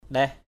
/ɗɛh/